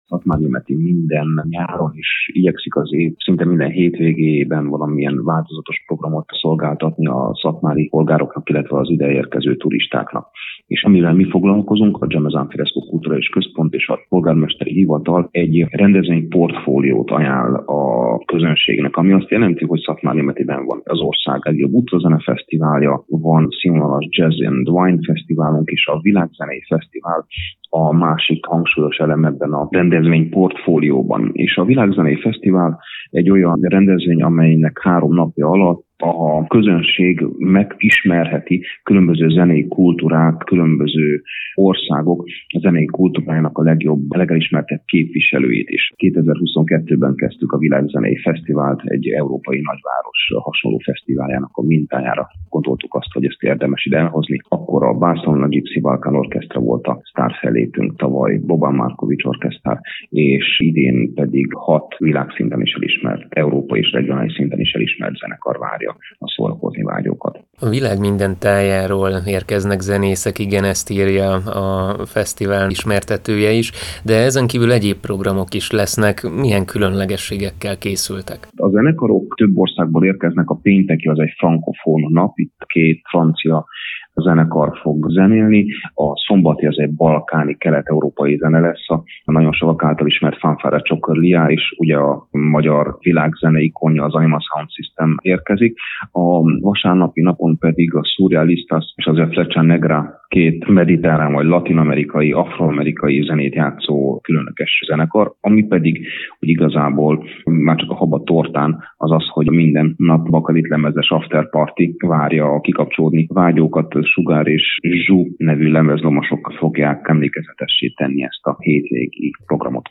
Címlap » Műsorok » Napirend » Pezsdítő nyárzárás Szatmárnémetiben